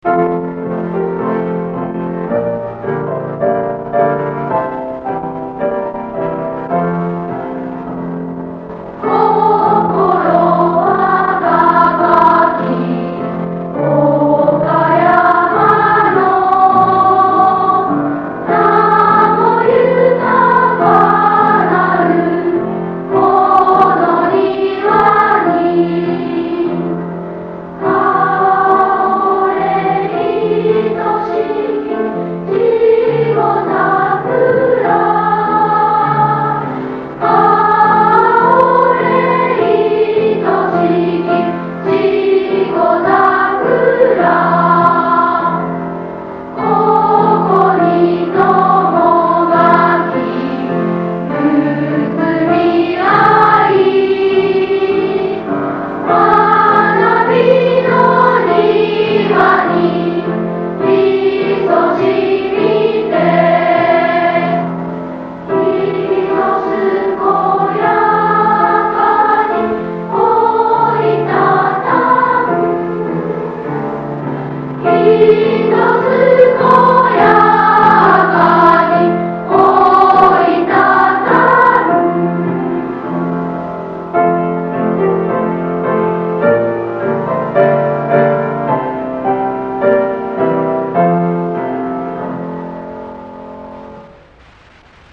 校歌
作詞・作曲　蓑和　秀華